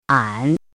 《埯》,埯的意思|埯的读音
ǎn
拼音： ǎn